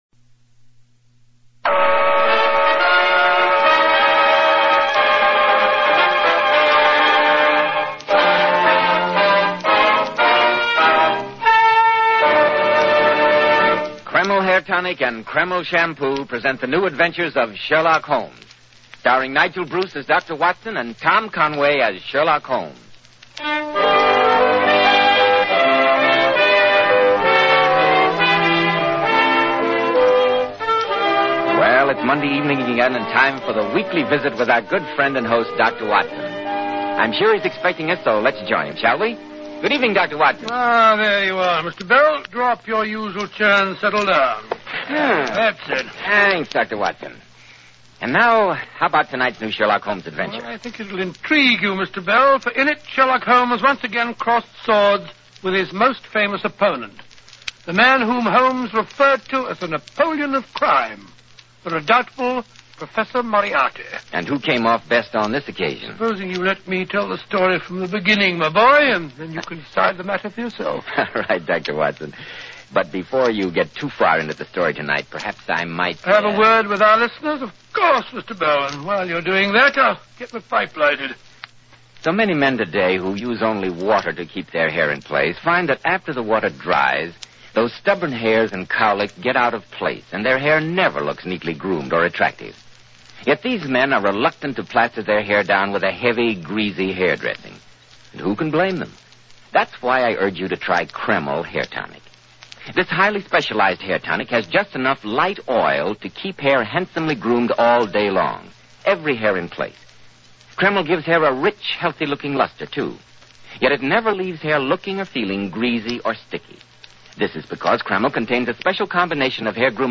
Radio Show Drama with Sherlock Holmes - The Harley Street Murders 1947